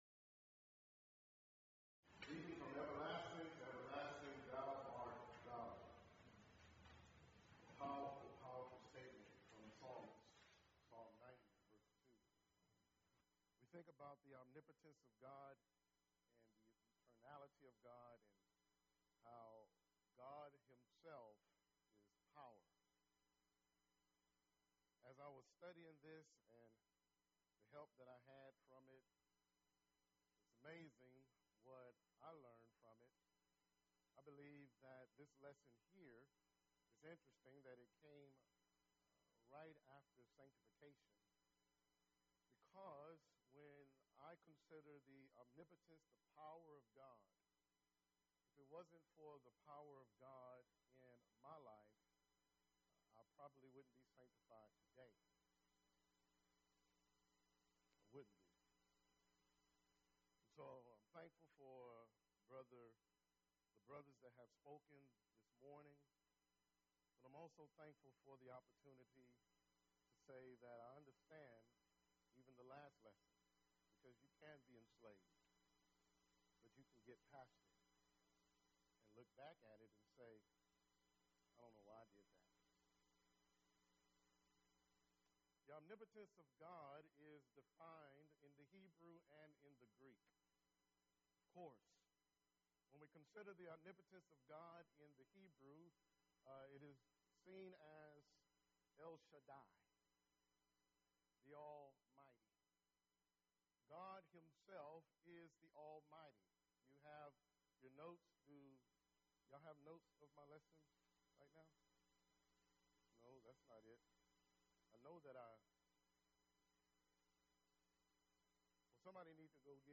Event: 4th Annual Men's Development Conference
lecture